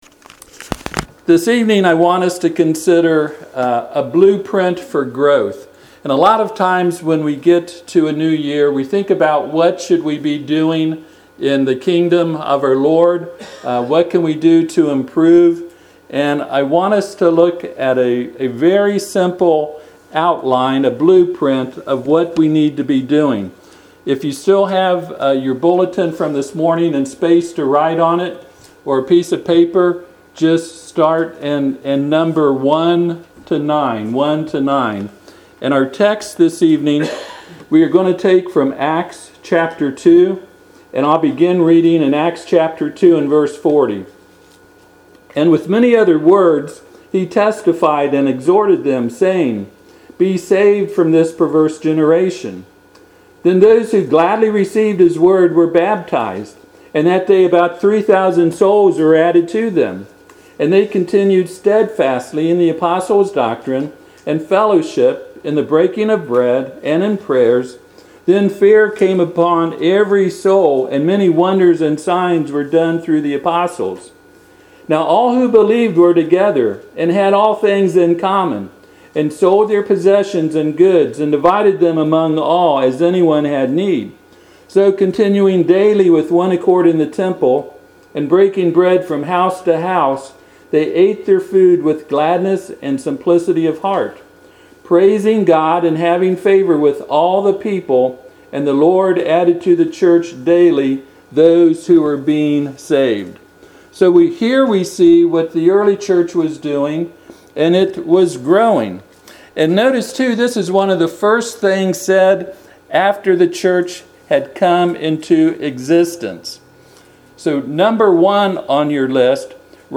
Acts 2:42-47 Service Type: Sunday PM « Sermon on the Mount